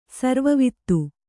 ♪ sarva vittu